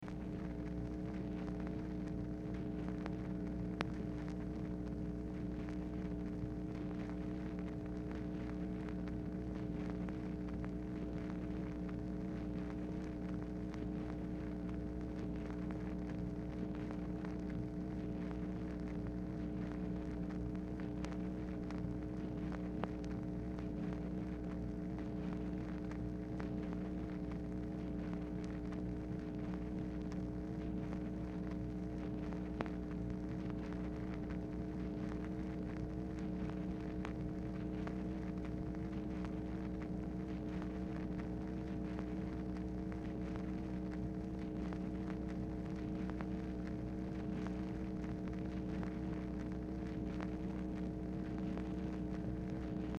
Telephone conversation # 6259, sound recording, MACHINE NOISE, 11/6/1964, time unknown | Discover LBJ
Format Dictation belt
LBJ Ranch, near Stonewall, Texas
Specific Item Type Telephone conversation